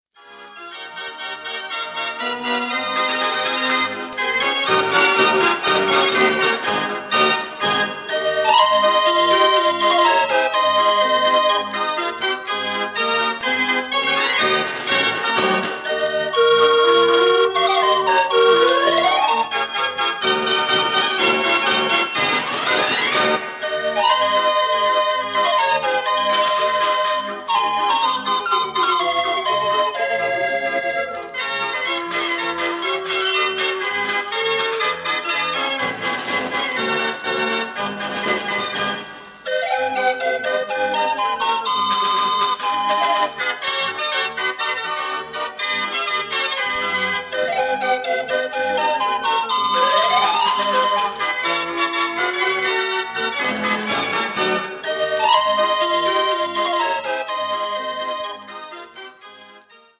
Formaat 78 toerenplaat, schellak